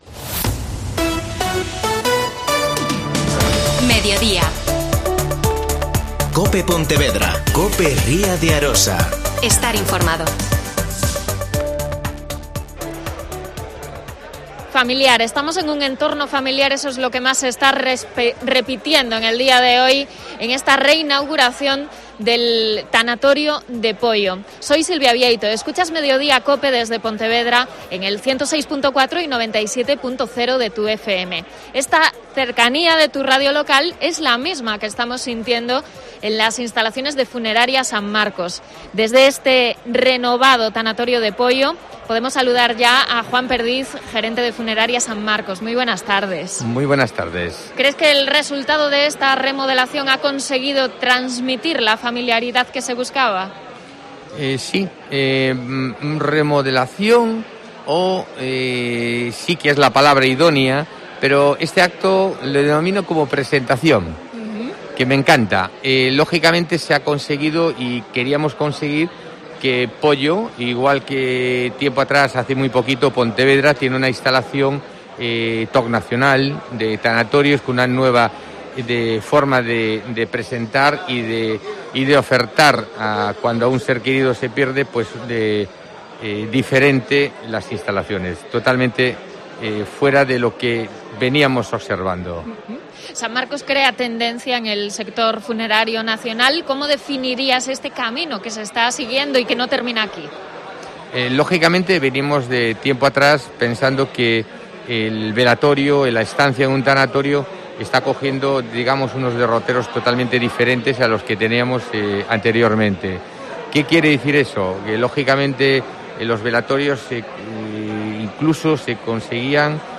Redacción digital Madrid - Publicado el 01 dic 2021, 14:12 - Actualizado 18 mar 2023, 05:29 1 min lectura Descargar Facebook Twitter Whatsapp Telegram Enviar por email Copiar enlace Programa Especial desde el Tanatorio de Poio.